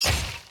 SwordHit.wav